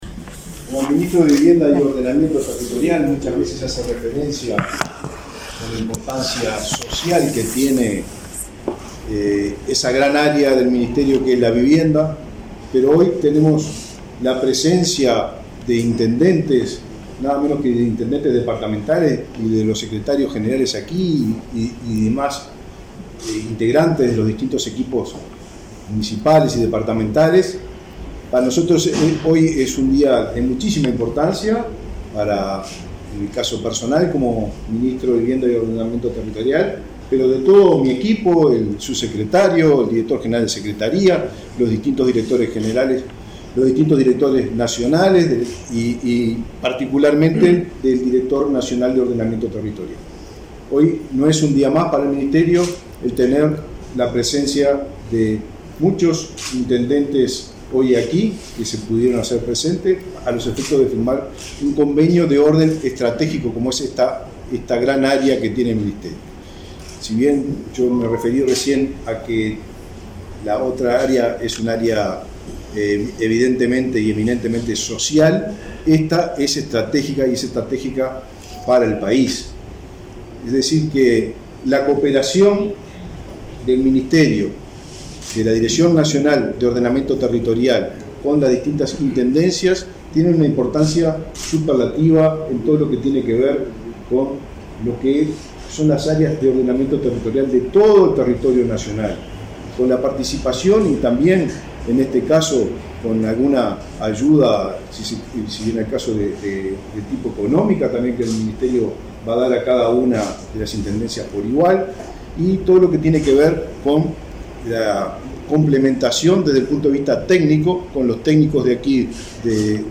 Palabras del ministro de Vivienda, Raúl Lozano
Este miércoles 30 en Montevideo, el ministro de Vivienda, Raúl Lozano, participó del acto de firma de convenio con 12 intendencias departamentales,